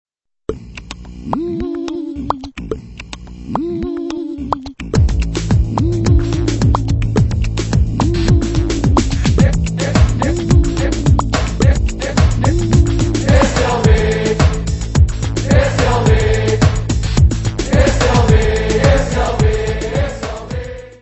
Music Category/Genre:  Soundtracks, Anthems, and others